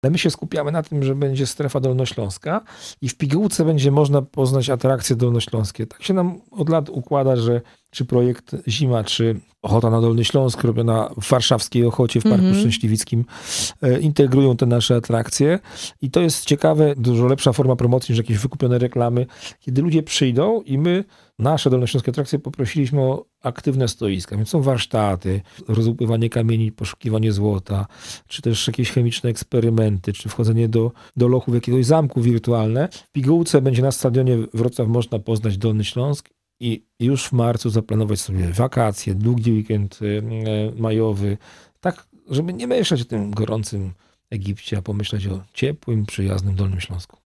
O wydarzeniu mówi Krzysztof Maj – członek Zarządu Województwa Dolnośląskiego.